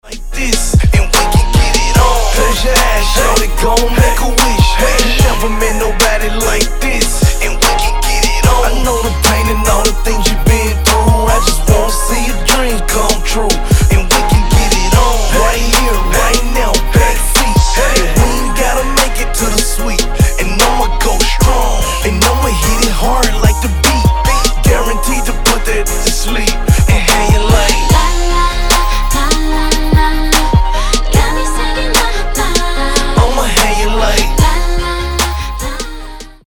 americký rapper